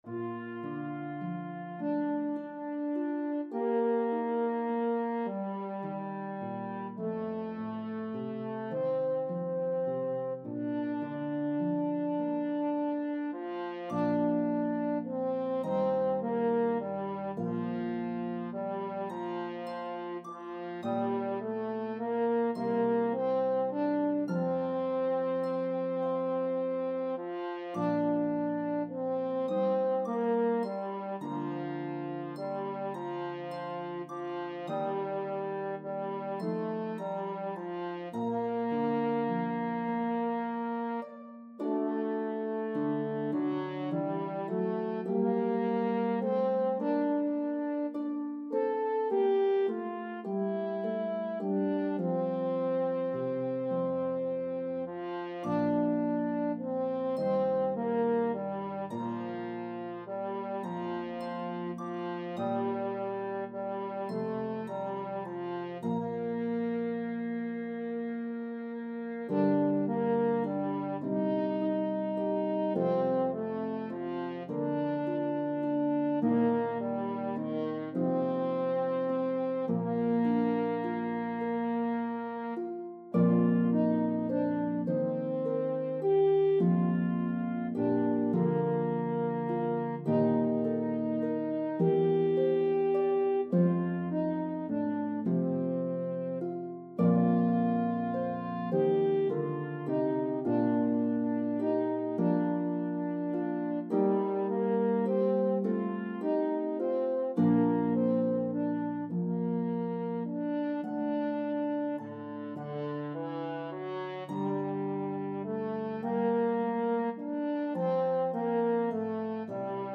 The melody is divided evenly between parts.